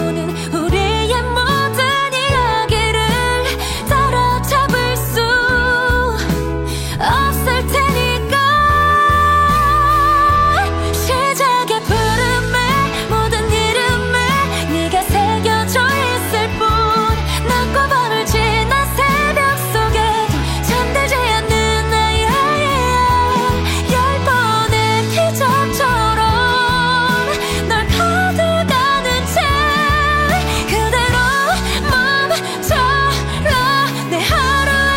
K-Pop Pop
2025-07-11 Жанр: Поп музыка Длительность